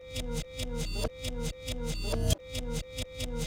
reverse loop.wav